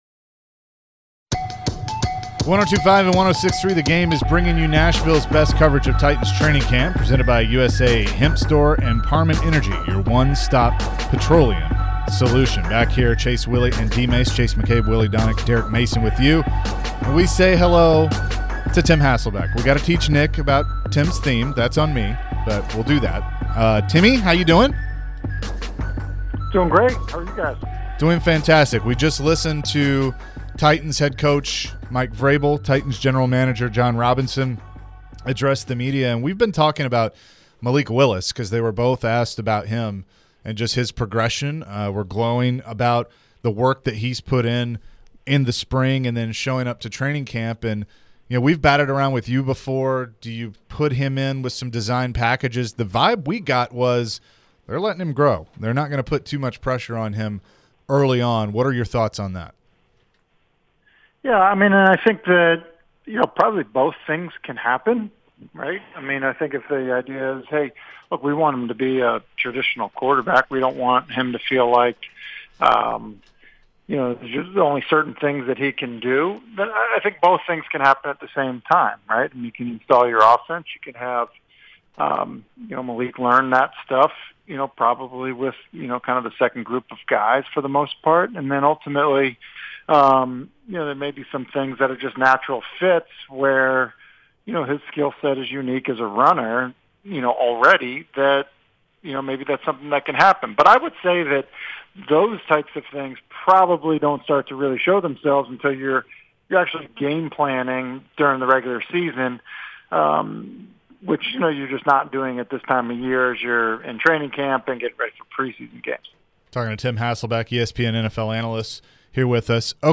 Tim Hasselbeck Full Interview (07-26-22)